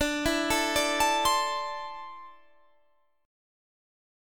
Listen to D7sus2 strummed